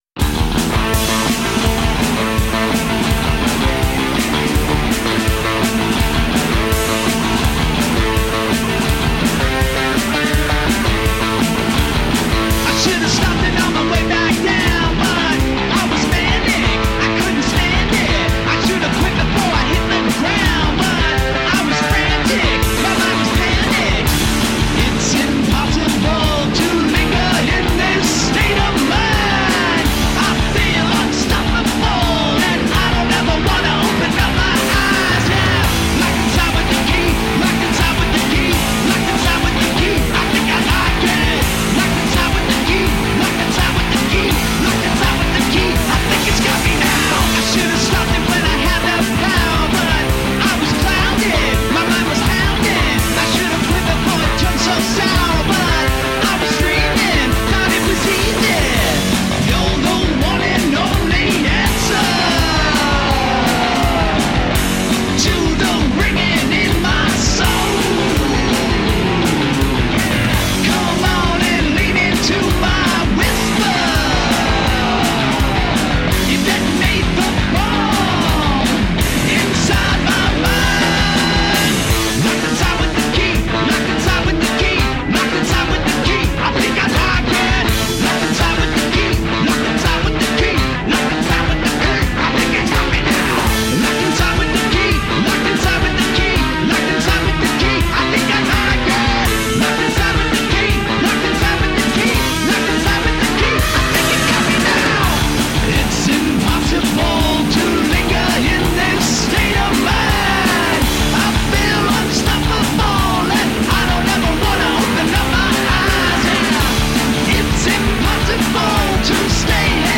Edgy, gritty rock for music tastemakers.
Tagged as: Alt Rock, Other, Vocal, Electric Guitar